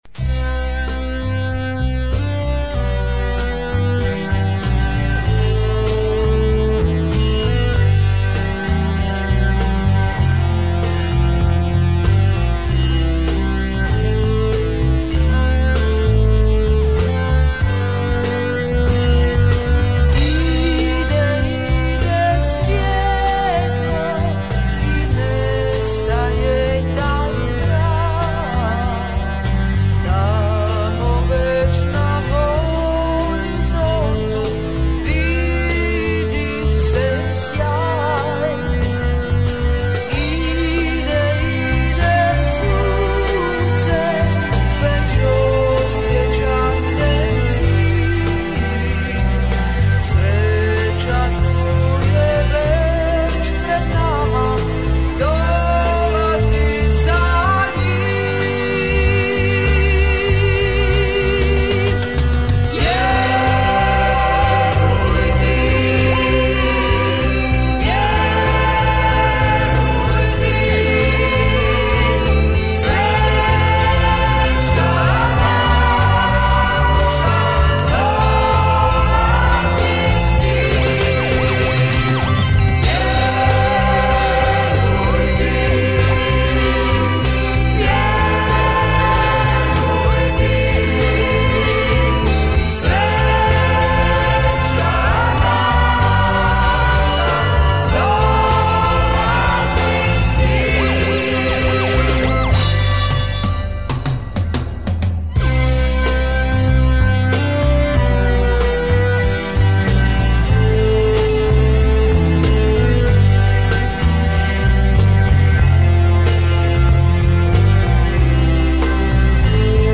Записал когда-то с 45-ки югославской.